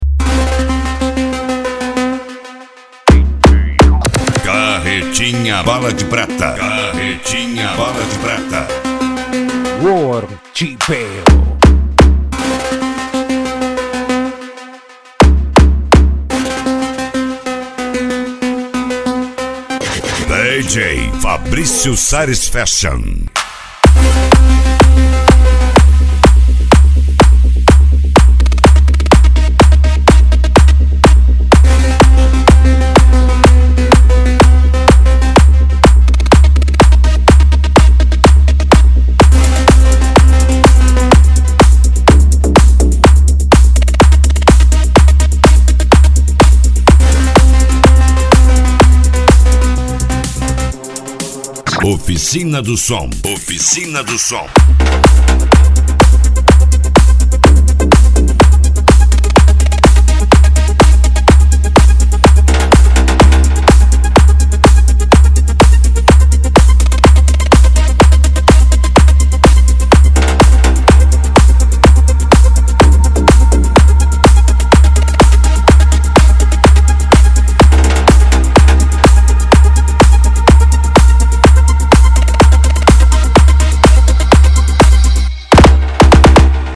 Deep House
Remix
SERTANEJO
Sertanejo Universitario